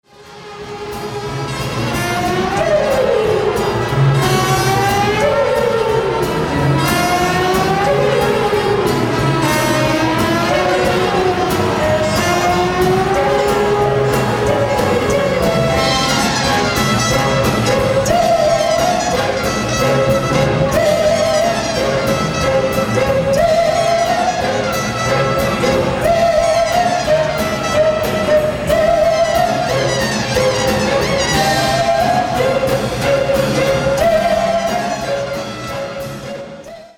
Vocalists
Live